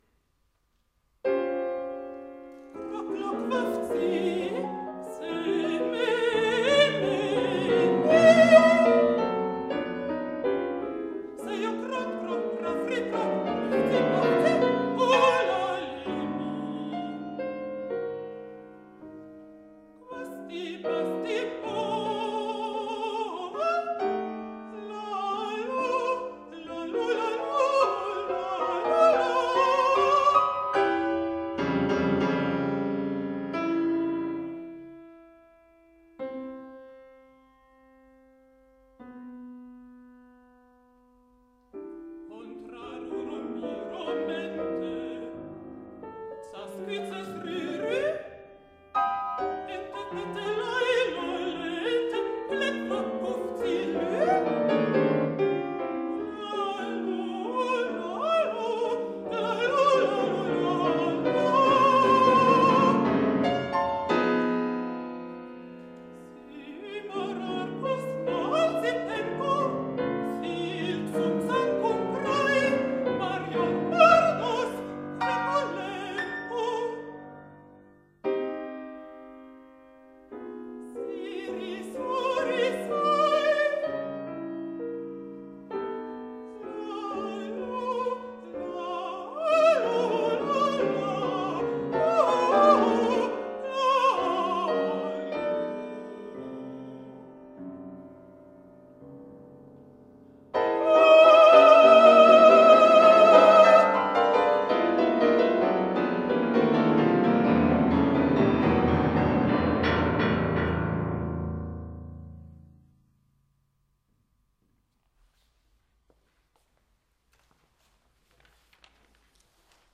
Alt
Klavier